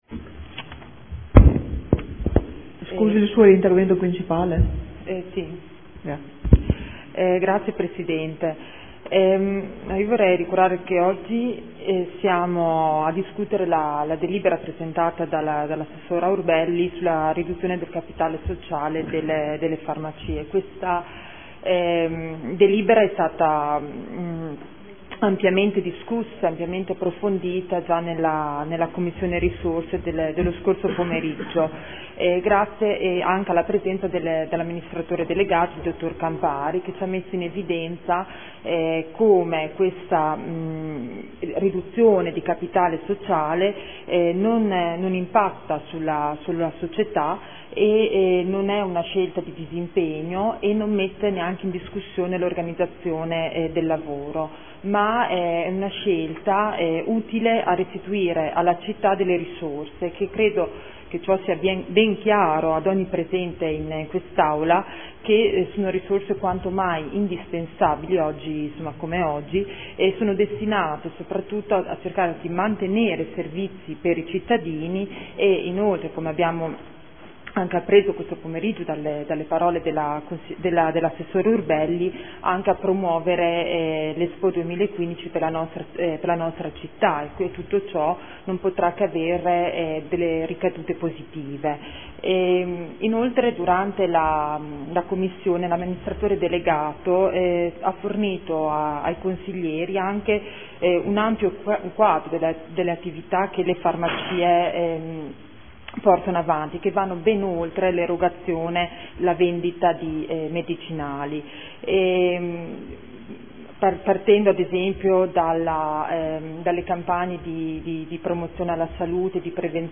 Grazia Baracchi — Sito Audio Consiglio Comunale